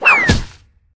refereePig_fall_impact_01.ogg